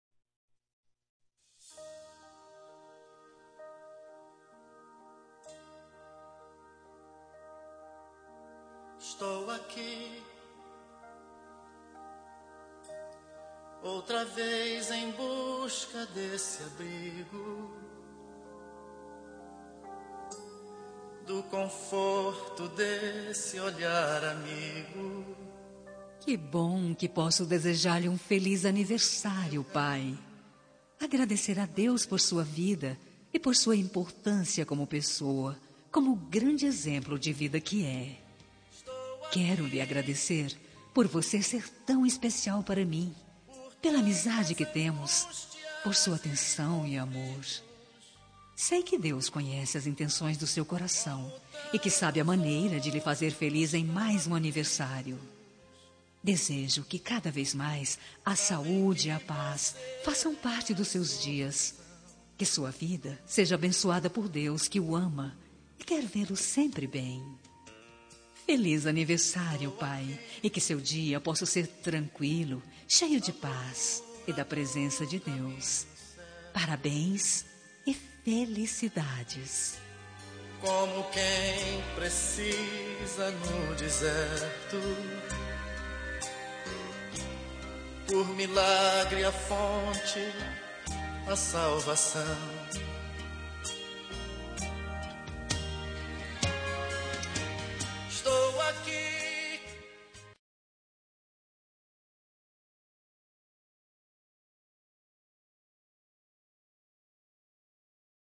Telemensagem de Aniversário de Pai – Voz Feminina – Cód: 1479 Religiosa